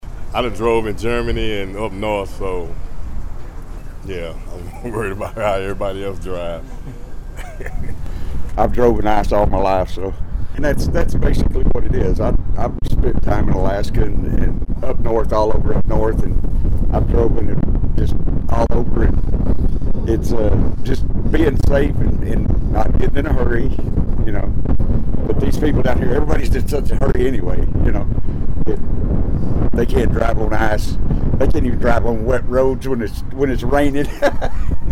I pulled into a QuikTrip and was pleasantly surprised that, right away, people filling their gas tank opened up. Their verdict: People in Texas don’t know how to drive when it’s icy:
Ice-Driving.mp3